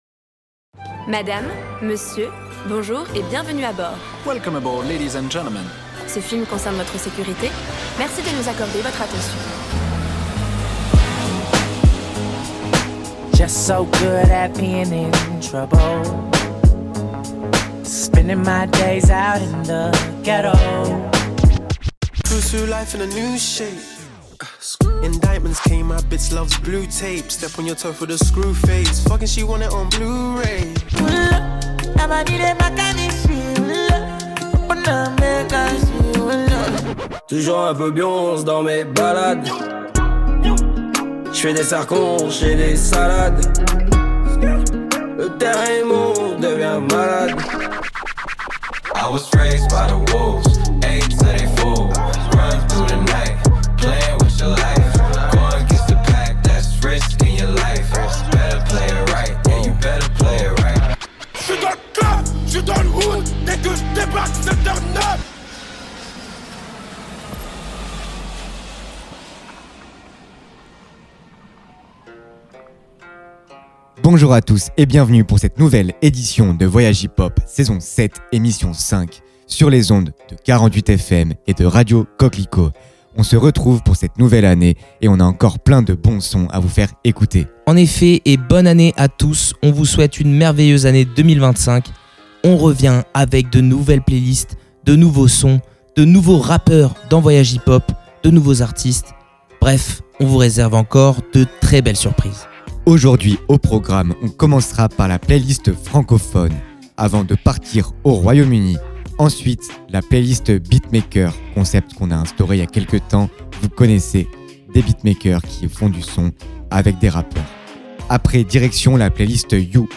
Emission du mardi 21 janvier à 21h00